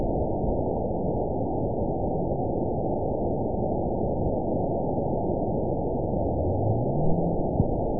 event 920317 date 03/15/24 time 18:58:39 GMT (1 month, 2 weeks ago) score 9.44 location TSS-AB05 detected by nrw target species NRW annotations +NRW Spectrogram: Frequency (kHz) vs. Time (s) audio not available .wav